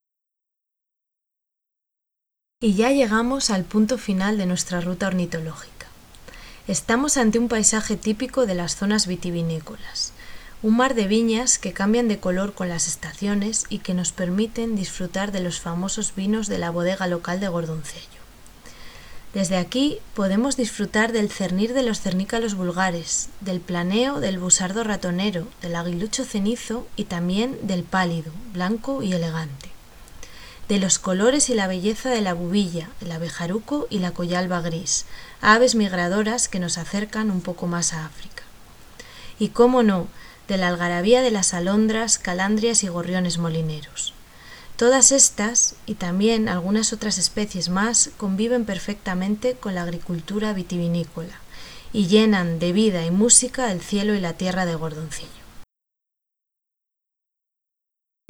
Audio descripción: